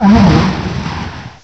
direct_sound_samples
cry_not_tornadus.aif